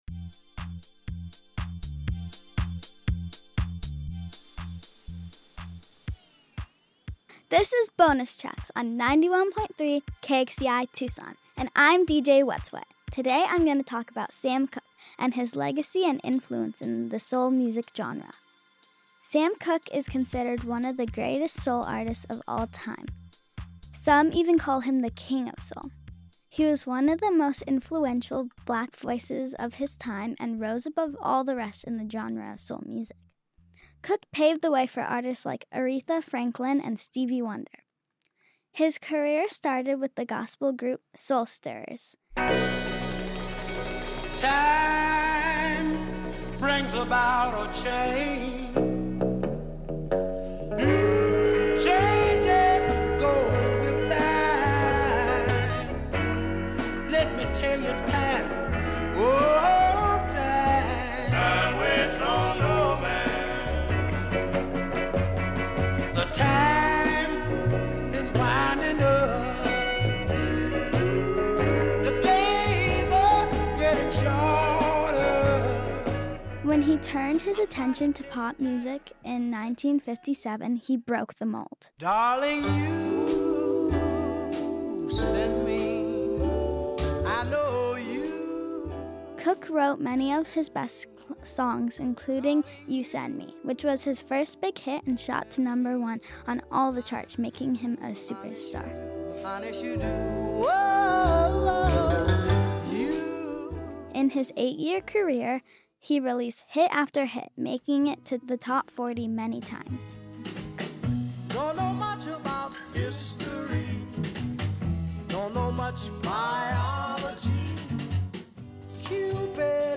From the early gospel days with The Soul Stirrers to his chart-topping hits, Cooke shaped the sound of soul and paved the way for future icons, while making his mark as a business man and civil rights advocate. Produced at KXCI Tucson, with help from the education team, this show is made possible thanks to listener support!